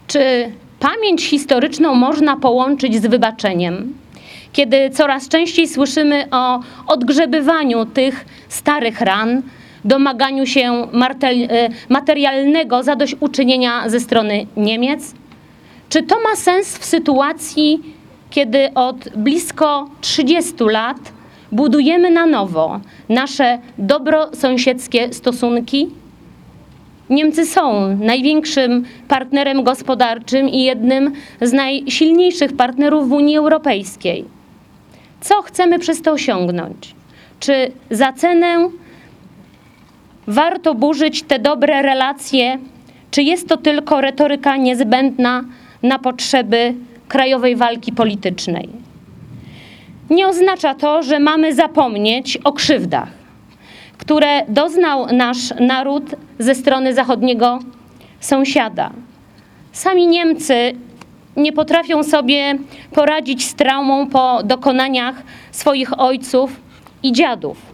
Tradycyjnie już rocznicowe obchody odbyły się  pod Pomnikiem Żołnierzy Września 1939 roku przy ulicy Wojska Polskiego, gdzie w południe spotkali się kombatanci, przedstawiciele władz, szkół, służb mundurowych i wojskowa.
Natomiast parlamentarzystka mówiła między innymi o budowaniu pokoju poprzez dobre relacje międzyludzkie. Pytała, czy żądanie materialnego zadośćuczynienia od Niemiec ma sens, kiedy od 30 lat budowane są dobre relacje.